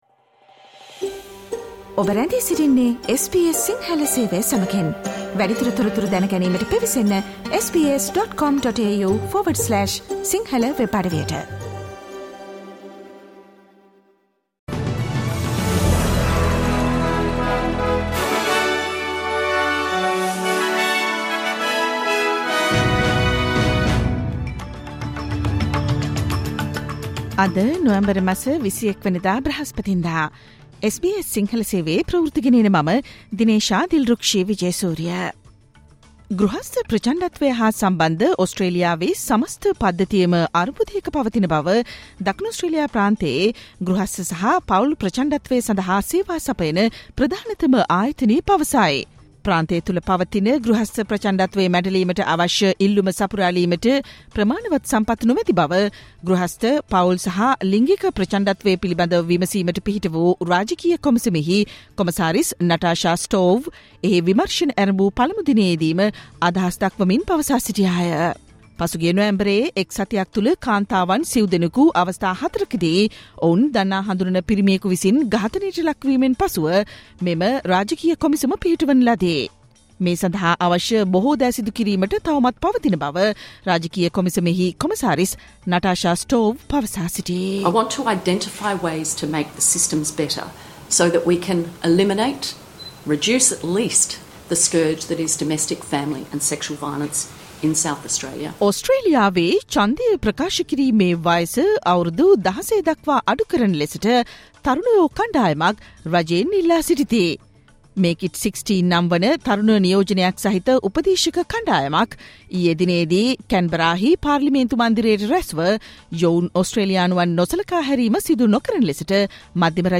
Australian news in Sinhala, foreign news, and sports news in brief.